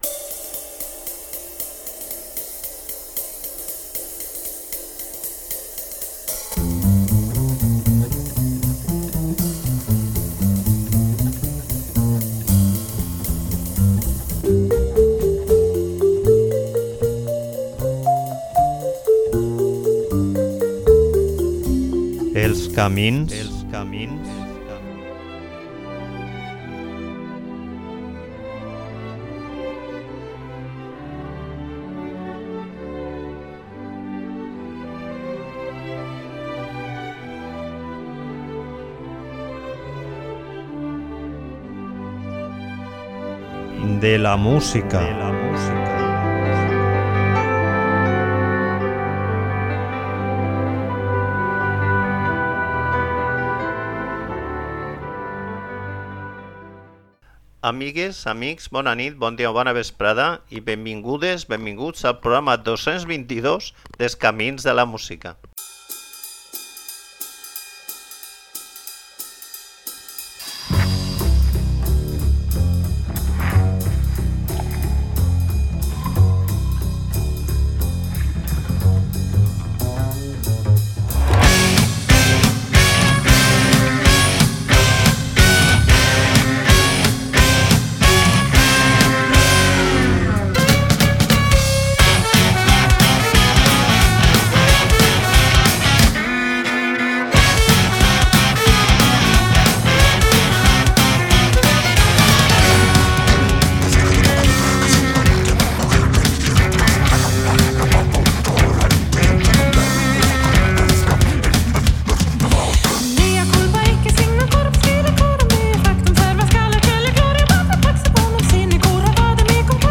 Música lliure de les cadenes dels mercaders-3 Programa 222 Més músiques que, en l'àmbit del Jazz, fugen de les urpes dels corbs de les grans cadenes del Show-bussiness.